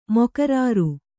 ♪ mokarāru